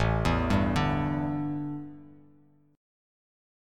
A7sus4 chord